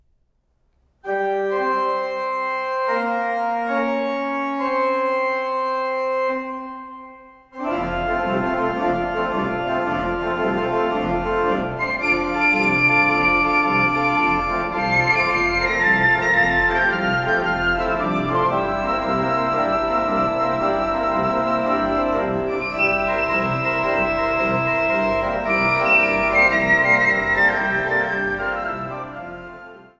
Orgelmusik
Flöte, Piccolo
Orgel